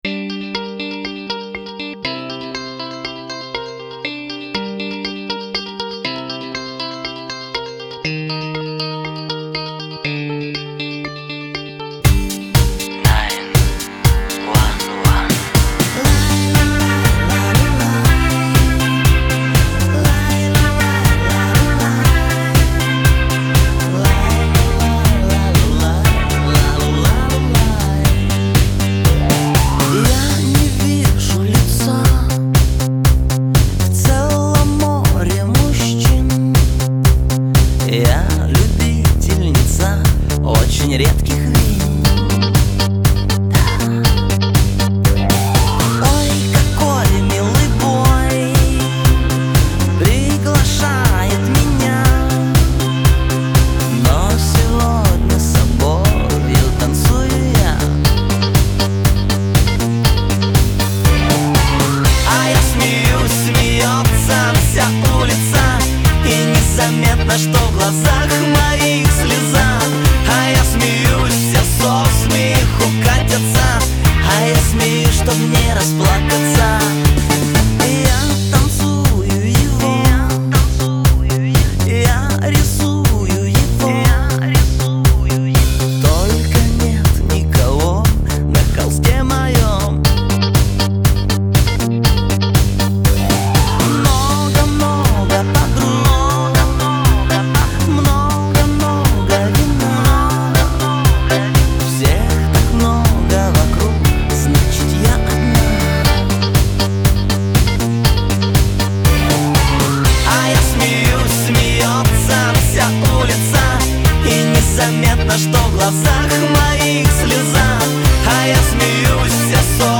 Грустная песенка с очень говорящим названием)